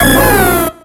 Cri de Celebi dans Pokémon X et Y.